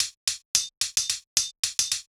UHH_ElectroHatB_110-04.wav